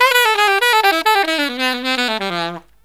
63SAXMD 06-R.wav